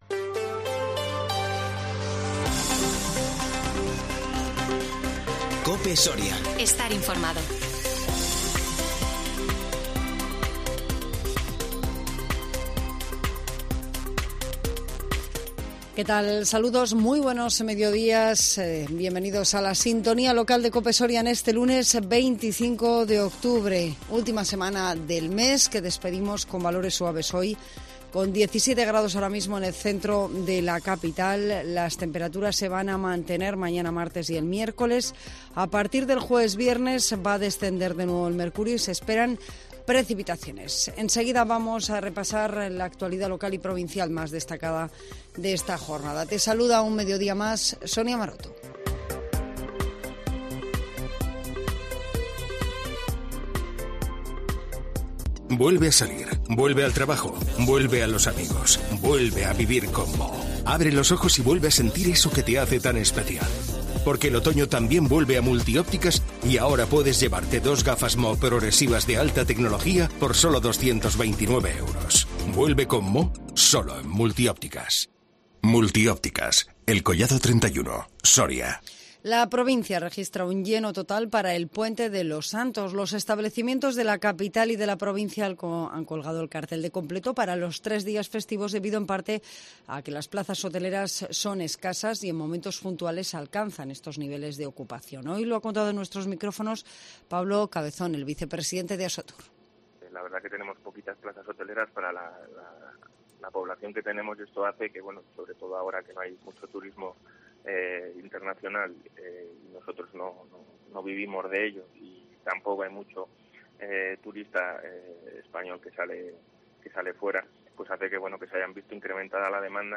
INFORMATIVO MEDIODÍA 25 OCTUBRE 2021